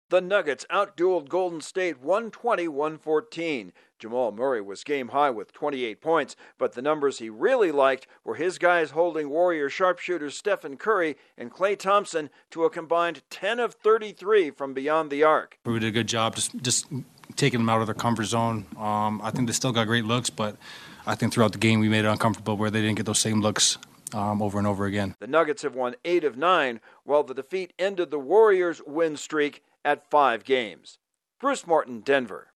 reports from Denver.